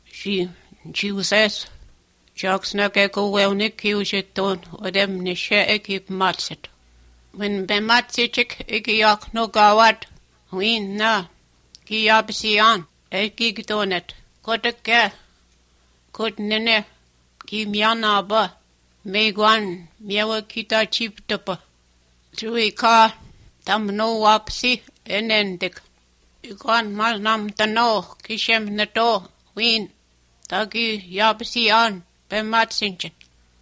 24 January 2017 at 6:41 am I’m hearing clicks.
I think what you are hearing is the high frequency parts of plosives, accentuated by the frequency response of the recording equipment.